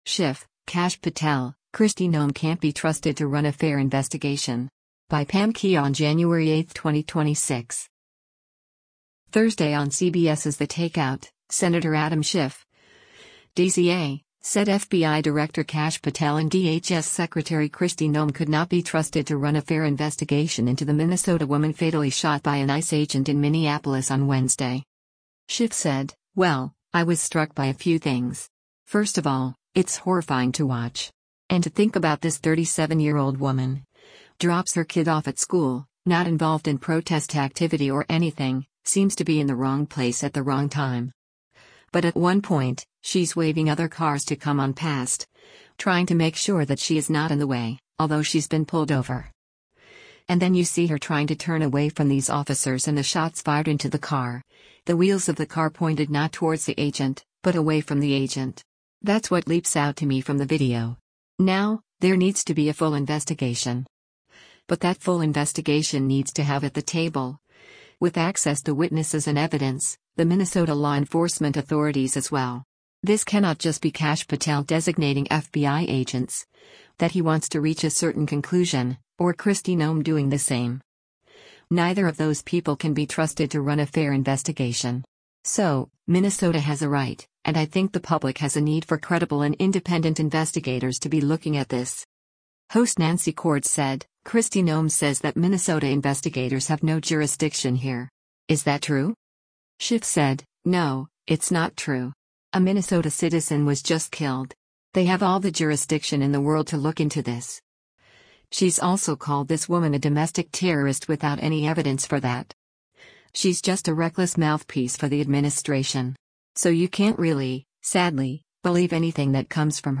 Thursday on CBS’s “The Takeout,” Sen. Adam Schiff (D-CA) said FBI Director Kash Patel and DHS Secretary Kristi Noem could not be trusted to run a fair investigation into the Minnesota woman fatally shot by an ICE agent in Minneapolis on Wednesday.